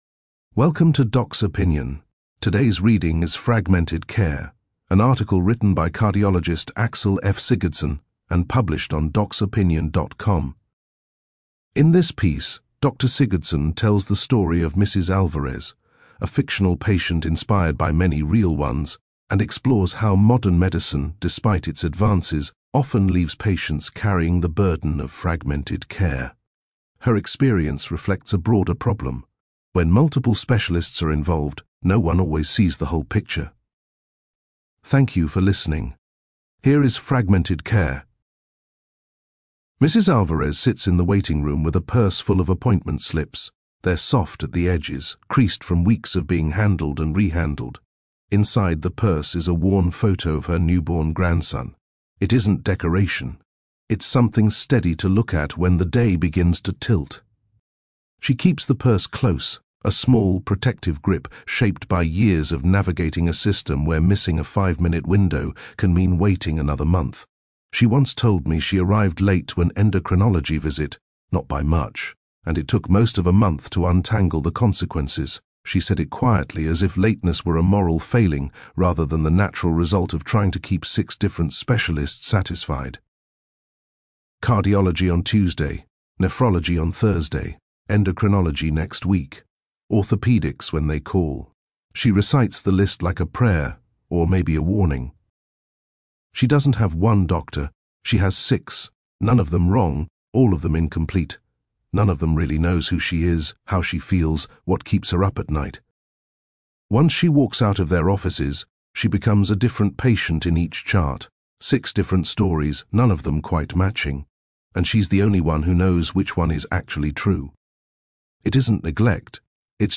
You can listen to this article, “The Fragmented Patient: Specialization and the Loss of the Whole”, narrated in full.